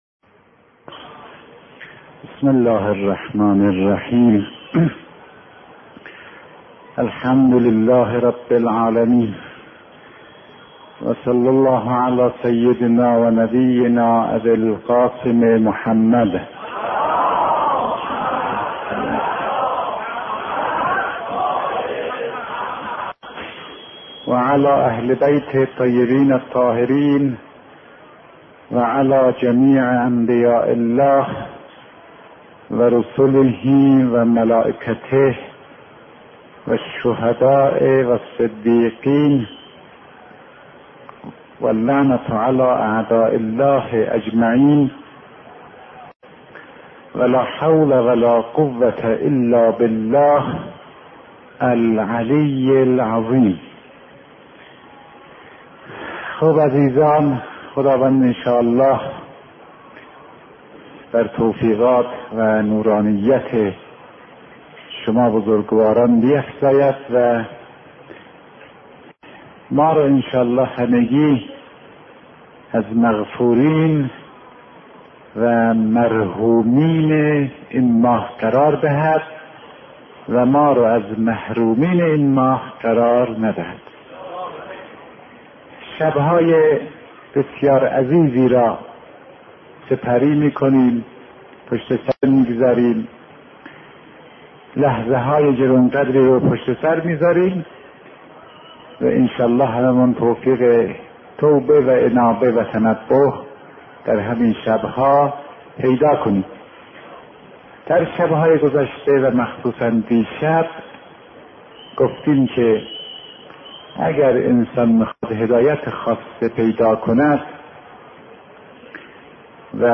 بررسی اهمیت ترک غیبت و گناه در دستیابی به هدایت خاصه و رشد معنوی، بر اساس سخنرانی استاد فاطمی‌نیا، همراه با نکات کلیدی در حفظ نورانیت و حالات معنوی.
بیانات عارف بزرگوار حضرت آیت الله فاطمی نیا، پیرامون مباحث اخلاق در قرآن با عنوان «ترک غیبت و گناه» / مدت زمان : 27 دقیقه